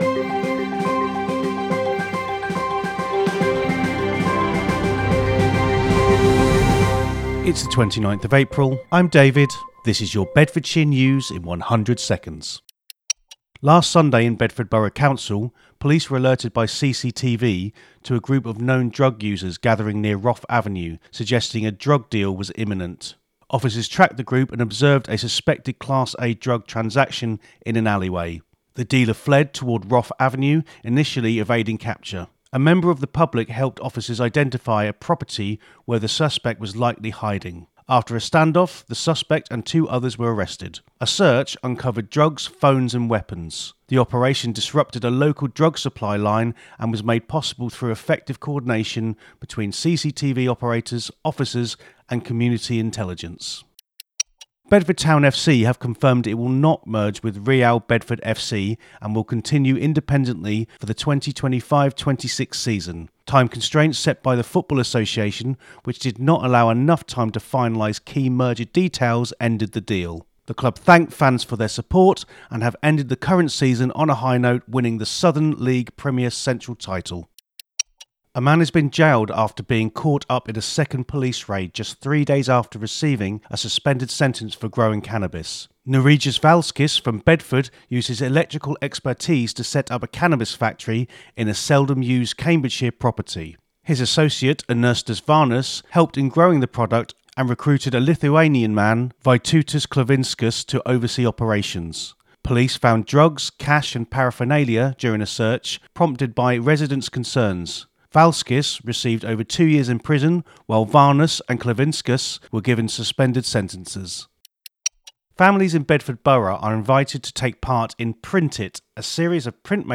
A free audio news roundup for Bedford and the greater Bedfordshire area, every weekday.